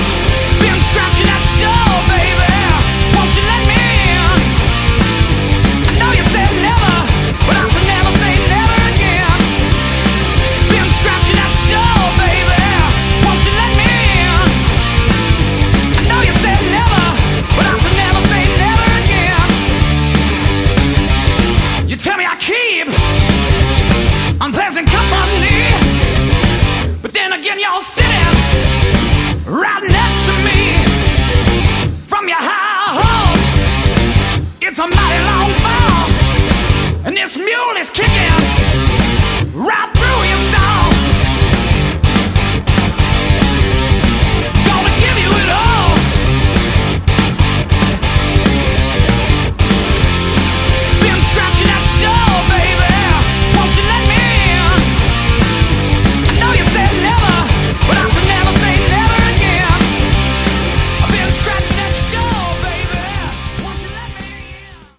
Category: Hard Rock
bass
vocals
guitar
drums